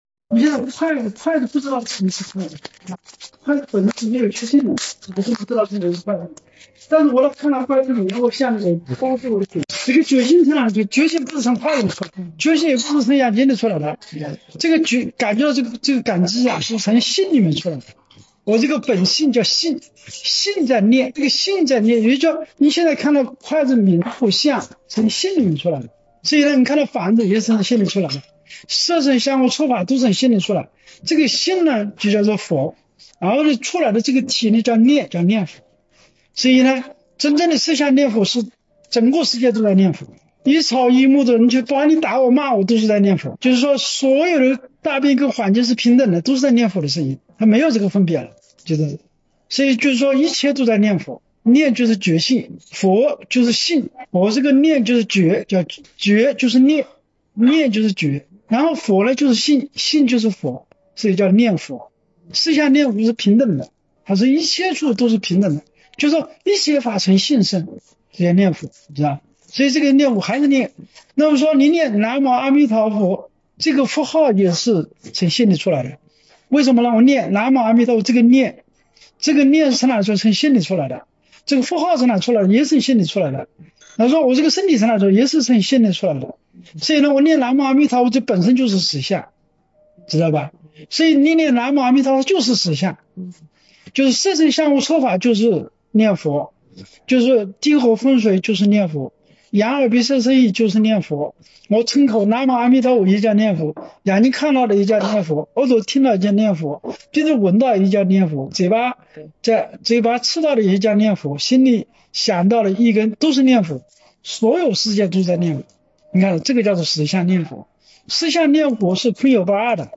实相念佛开示...